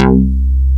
HOUSBAS1.wav